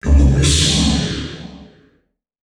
044male.wav